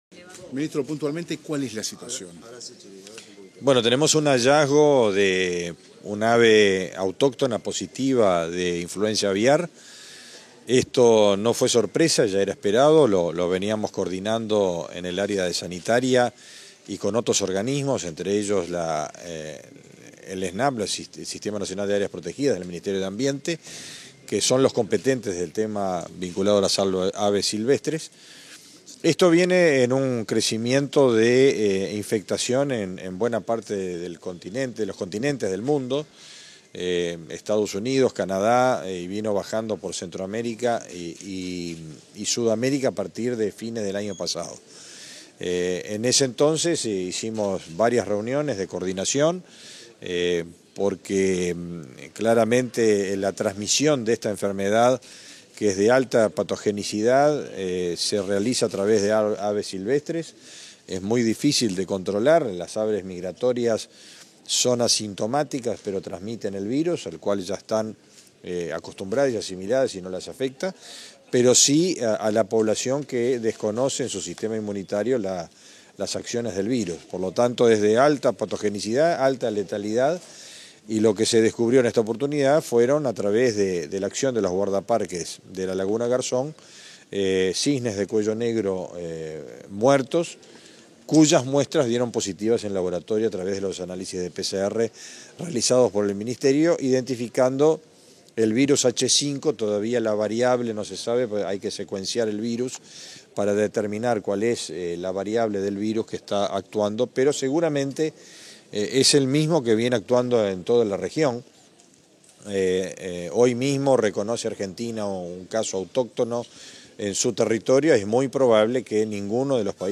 Declaraciones del ministro de Ganadería, Agricultura y Pesca, Fernando Mattos
Declaraciones del ministro de Ganadería, Agricultura y Pesca, Fernando Mattos 15/02/2023 Compartir Facebook X Copiar enlace WhatsApp LinkedIn Tras establecerse la emergencia sanitaria en todo el país, por el primer caso de influenza aviar, el ministro de Ganadería, Agricultura y Pesca, Fernando Mattos, fue entrevistado por medios periodísticos, este 15 de febrero.